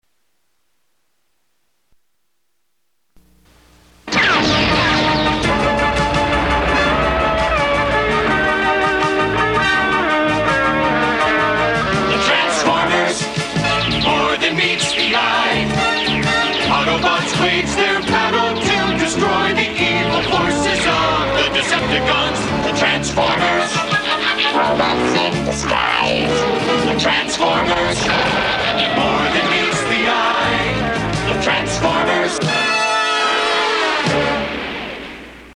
1 original show theme